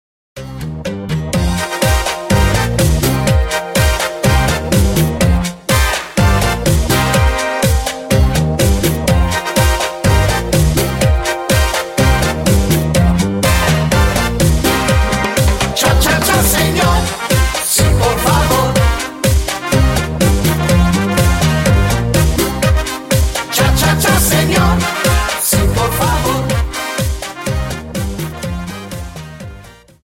Dance: Cha Cha 31